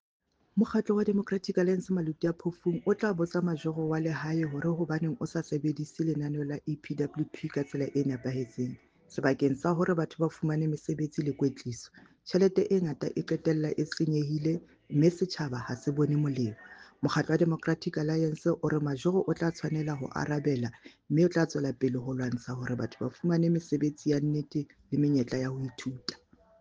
Sesotho soundbite by Cllr Ana Motaung.